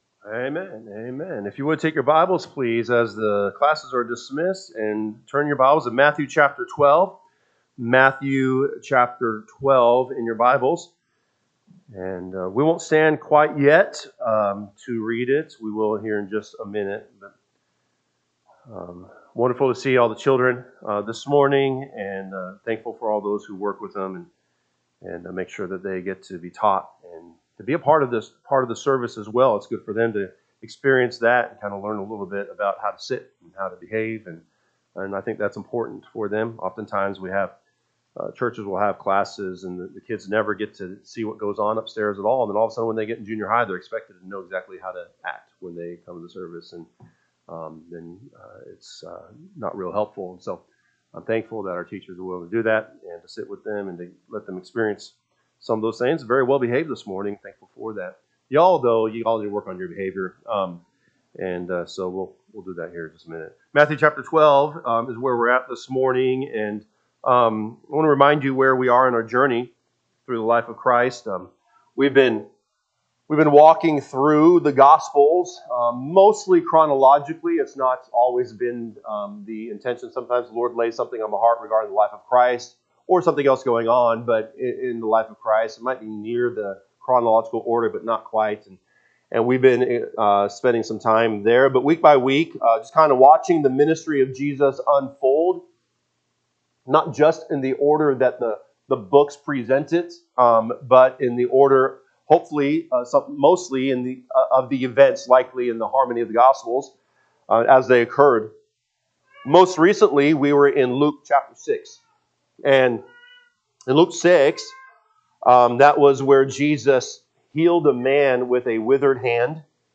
November 2, 2025 am Service Matthew 12:15-21 (KJB) 15 But when Jesus knew it, he withdrew himself from thence: and great multitudes followed him, and he healed them all; 16 And charged th…
Sunday AM Message